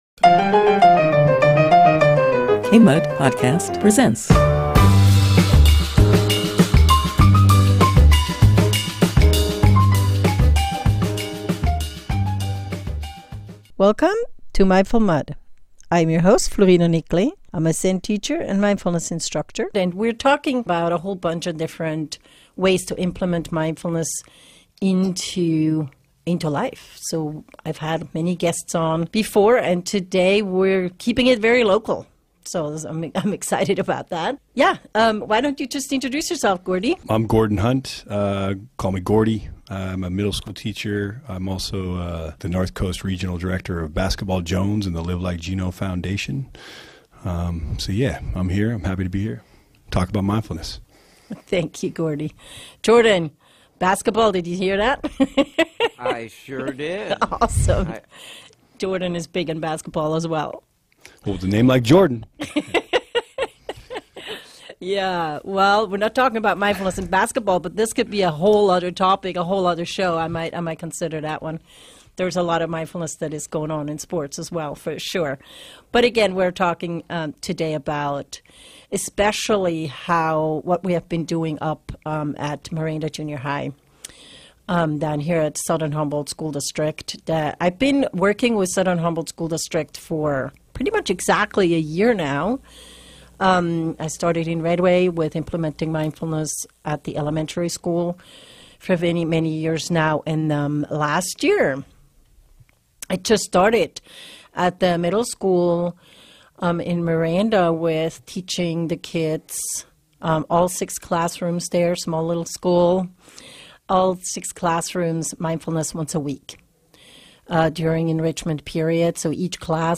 Hear about the transformational power of mindfulness practices for students and teachers. We discuss some of the science behind it, and our experiences from a mindfulness facilitator and a classroom teacher's perspective.